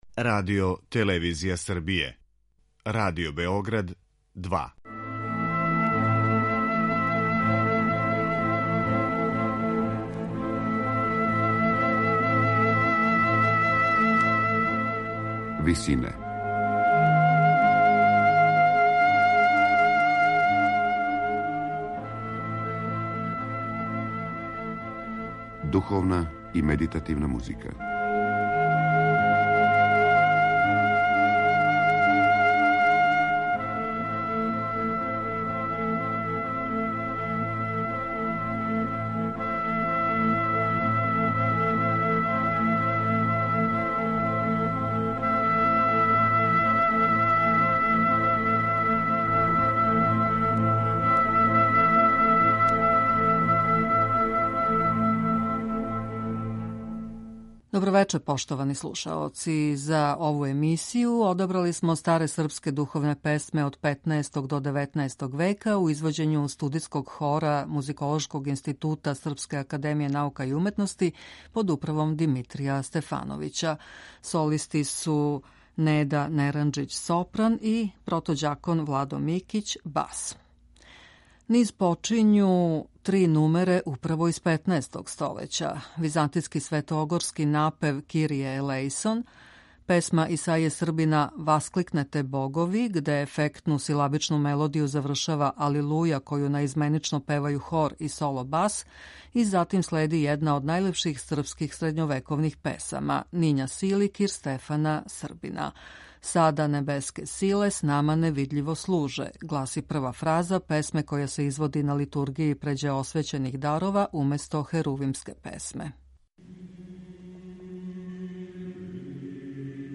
Српске народне божићне песме